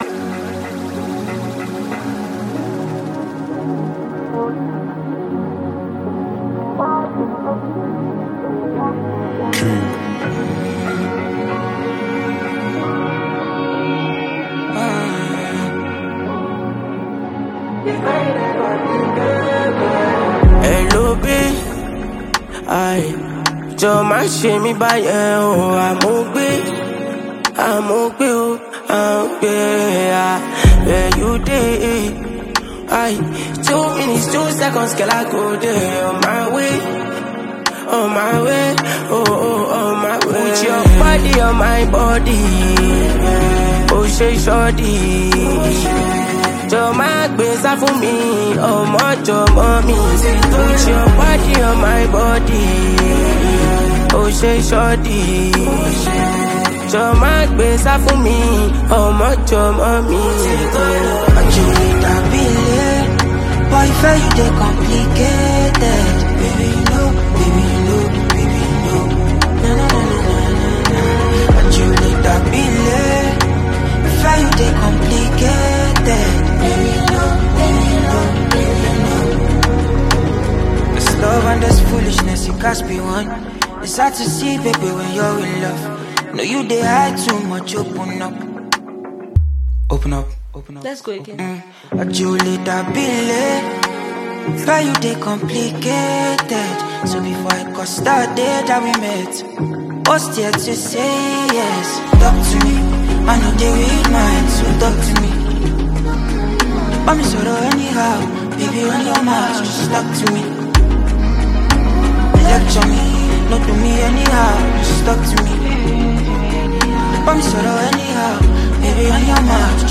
Nigerian renowned singer
Afrobeats